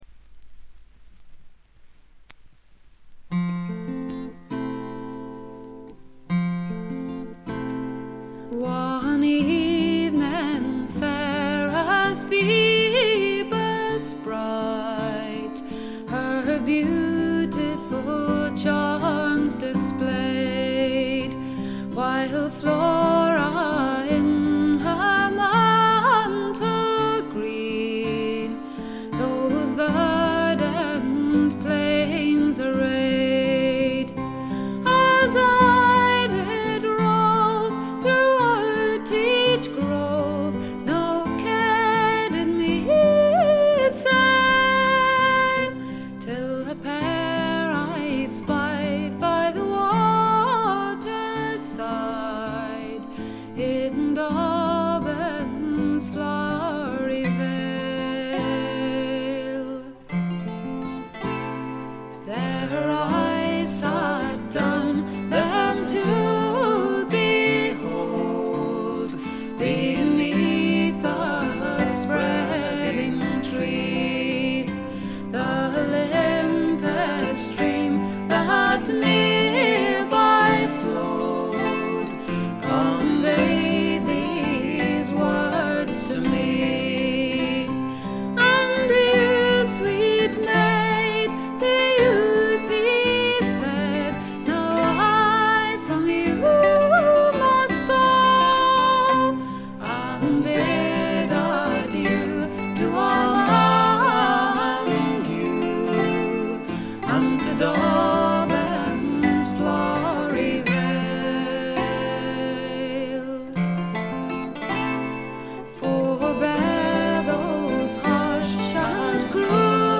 試聴曲は現品からの取り込み音源です。
Backing Vocals, Bouzouki
Harmonium [Indian]
Lead Vocals, Acoustic Guitar